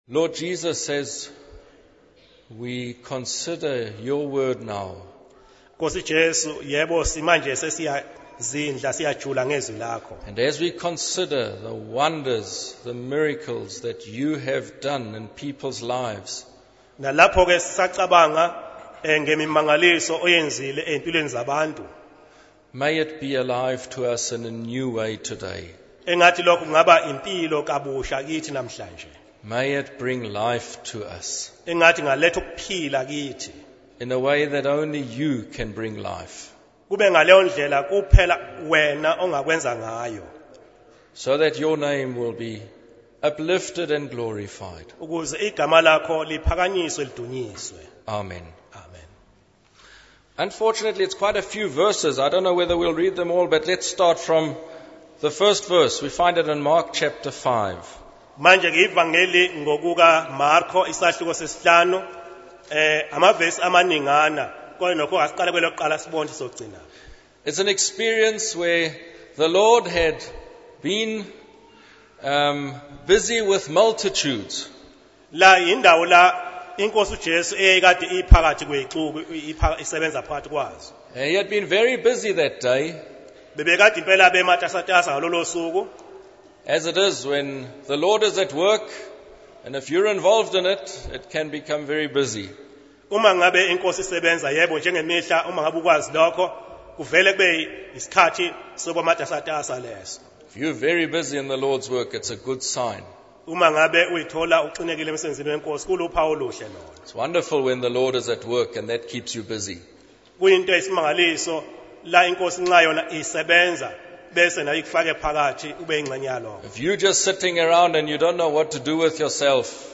In this sermon, the preacher describes the life of a lost soul who is trapped in a cycle of destructive behavior.